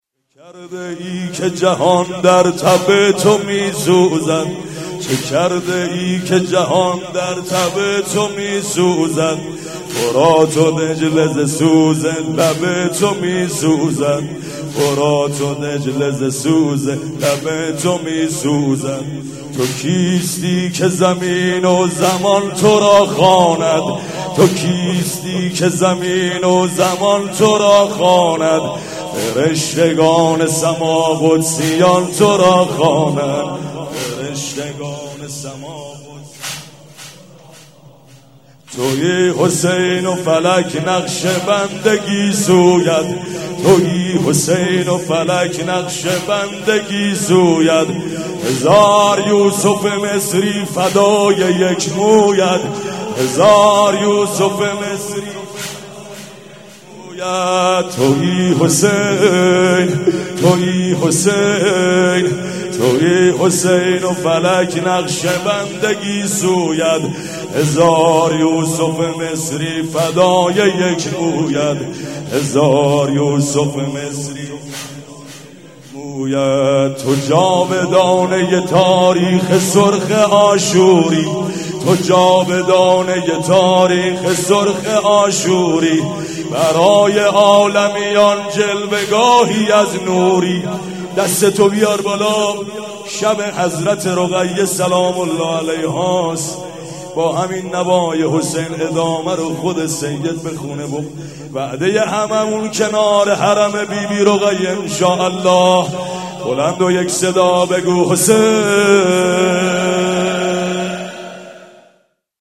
صوت مراسم شب سوم محرم ۱۴۳۷هیئت ریحانه الحسین(ع) ذیلاً می‌آید: